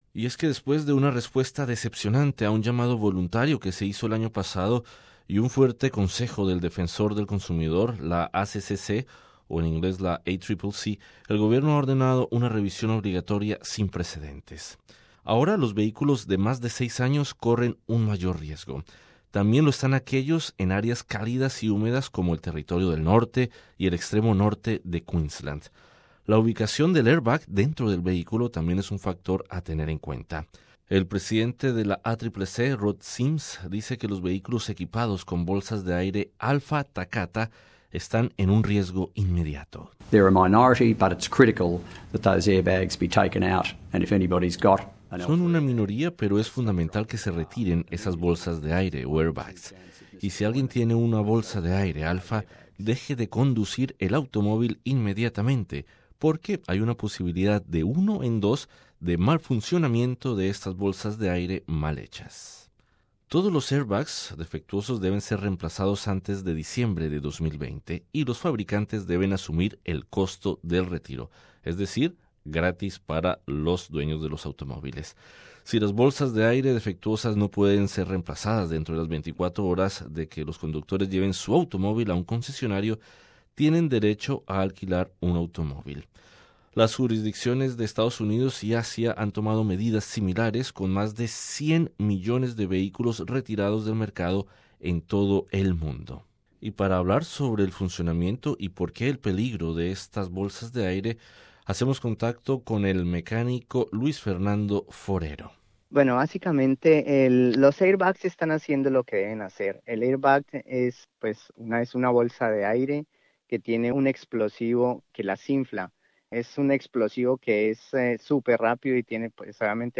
Sobre el funcionamiento y los defectos en estas bolsas de aire, conversamos con el mecánico